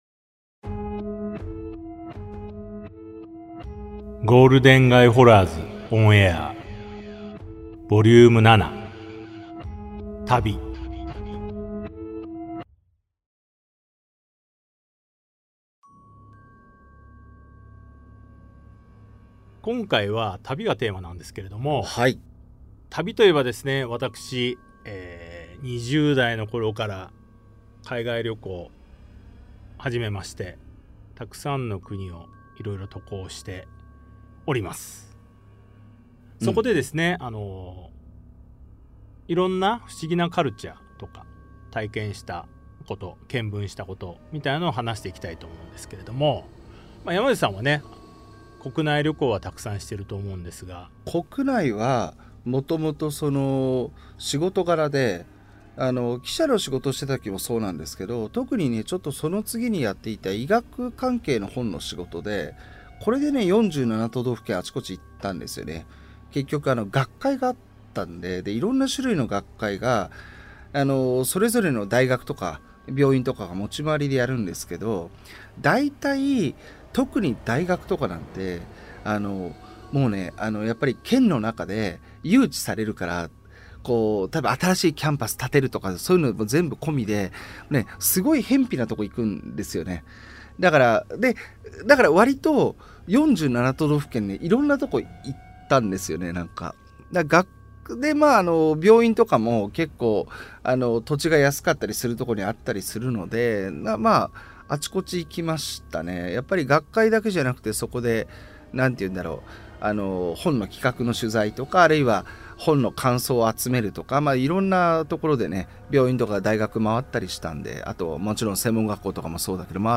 [オーディオブック] ゴールデン街ホラーズ ON AIR vol.07 旅